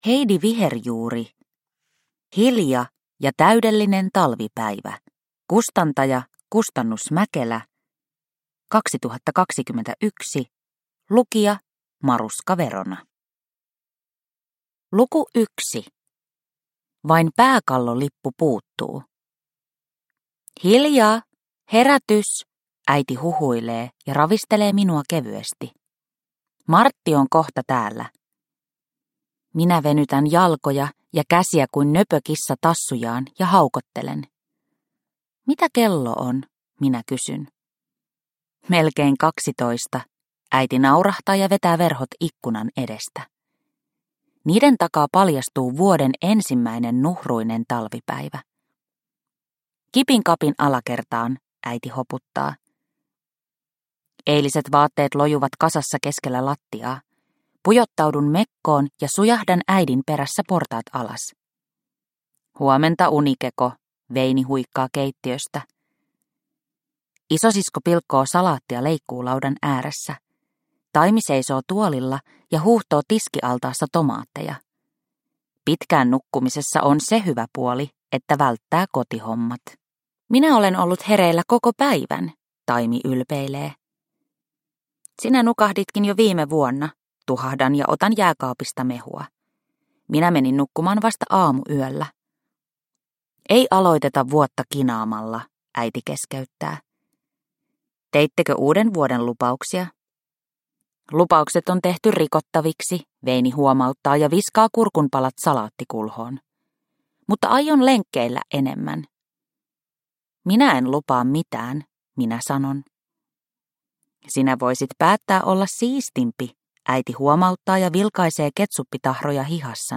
Hilja ja täydellinen talvipäivä – Ljudbok – Laddas ner